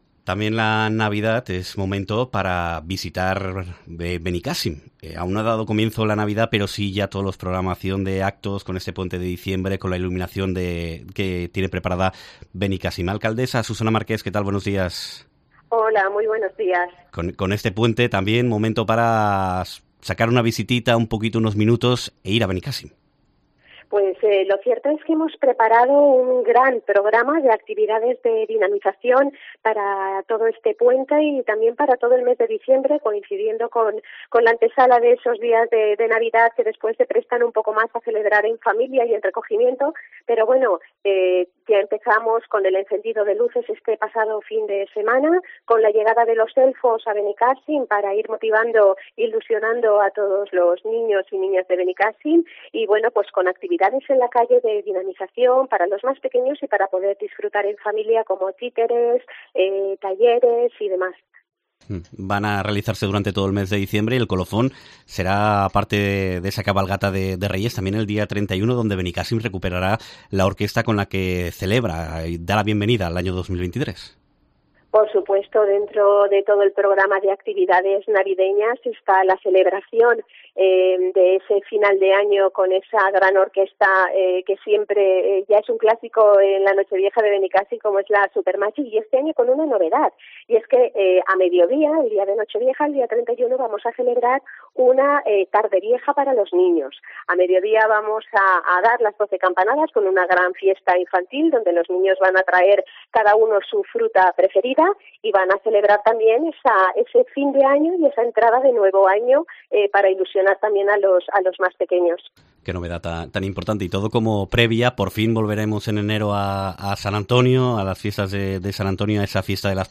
Entrevista
Benicàssim programa una Navidad con ocio para toda la familia, como explica en COPE la alcaldesa, Susana Marqués